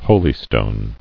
[ho·ly·stone]